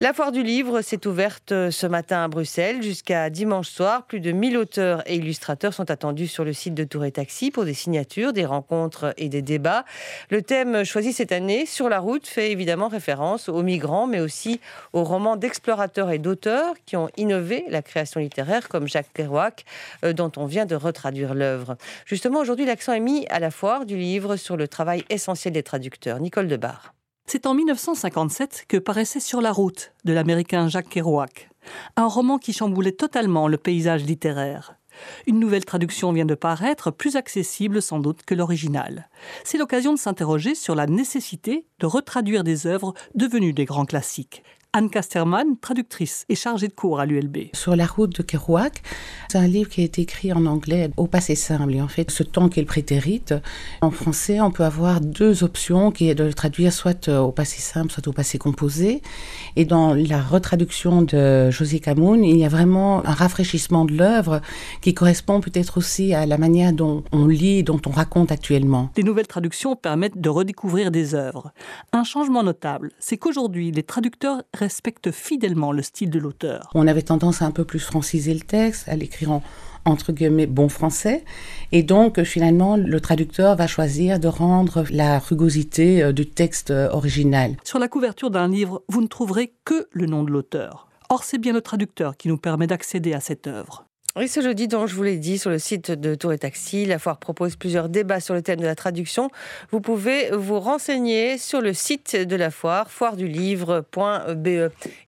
court reportage
diffusé sur La Première, radio de la RTBF, le jeudi 22 février 2018.